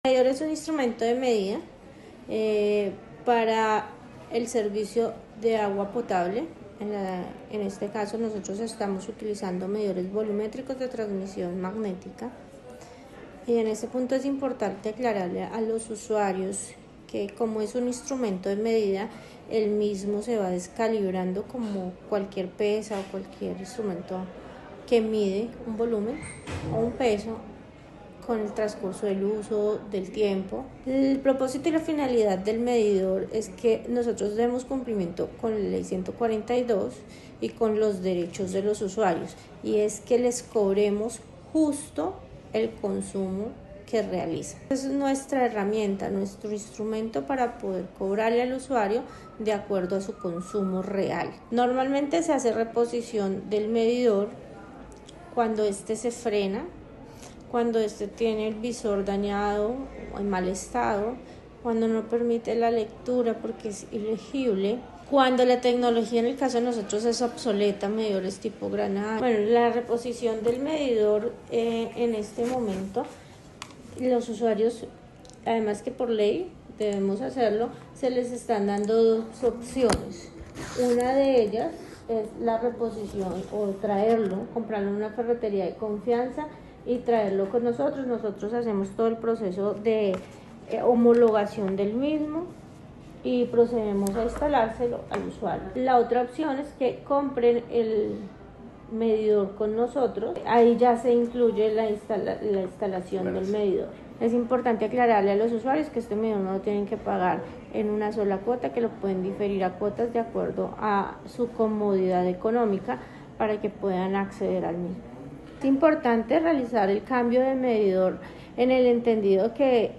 Transcripción comunicado de prensa.